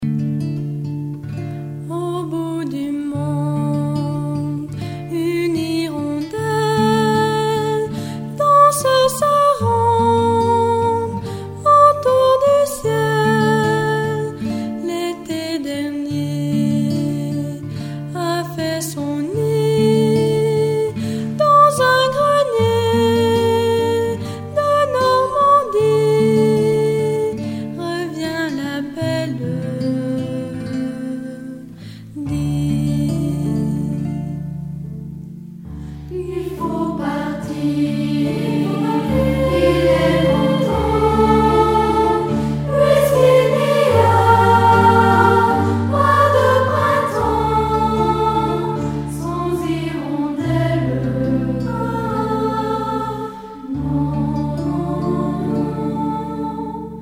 2000 - 2001 - Choeur d'enfants La Voix du Gibloux